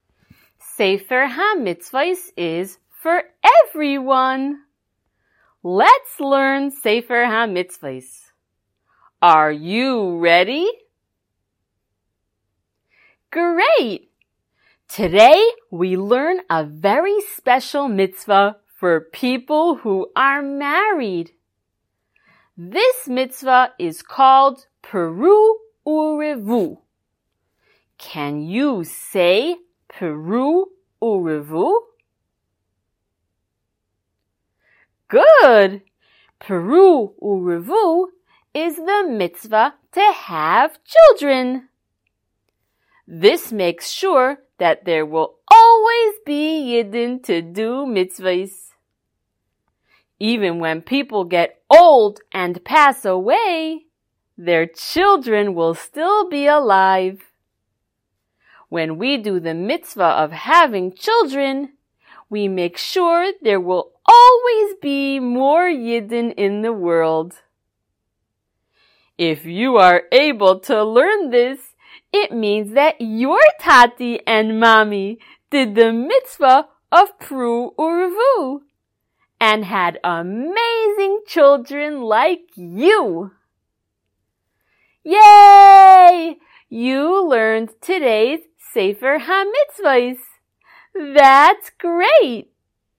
Color Shiur #76!
SmallChildren_Shiur076.mp3